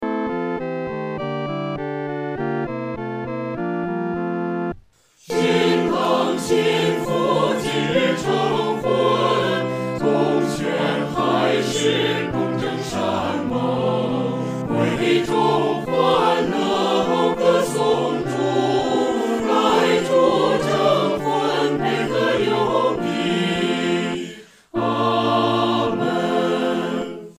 合唱
四声
本首圣诗由网上圣诗班录制